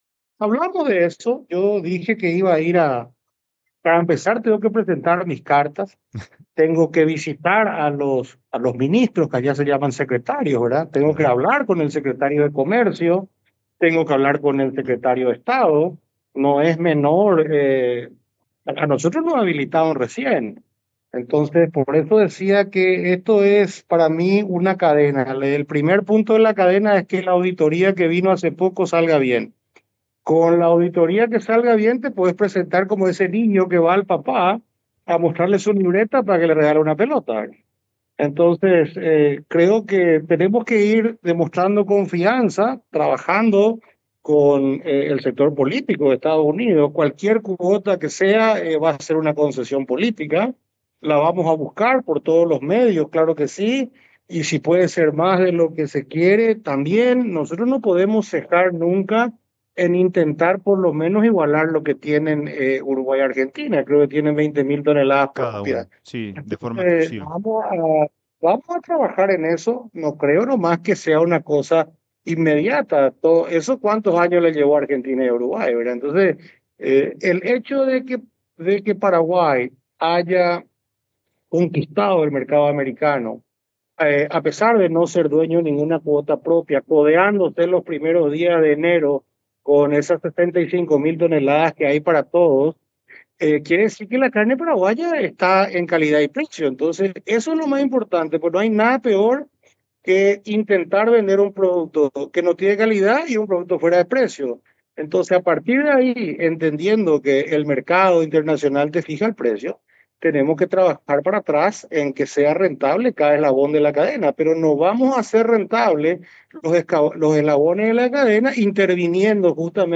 En una entrevista exclusiva con Valor Agregado en Radio Asunción, el embajador de Paraguay en Estados Unidos habló sobre los pedidos de la cadena cárnica del país para buscar la posibilidad de captar una cuota exclusiva en el mercado norteamericano, tal como tienen Argentina y Uruguay.